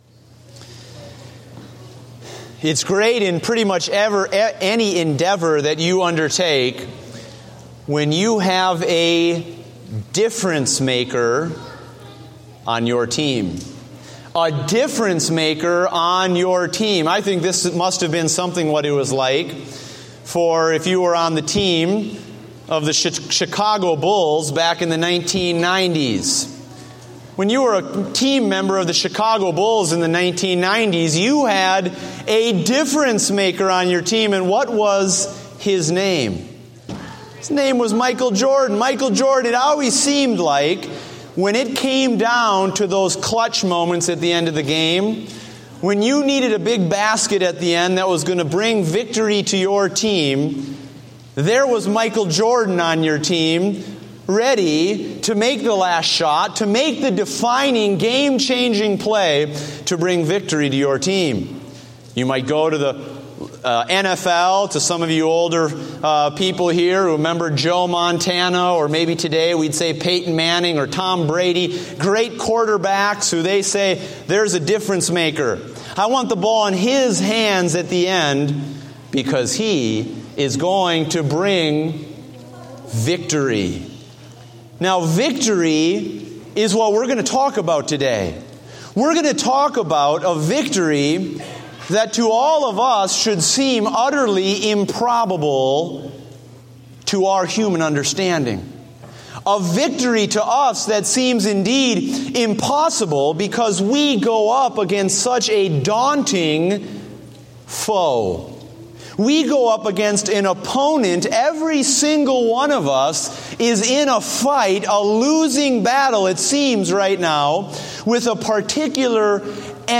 Date: September 13, 2015 (Morning Service)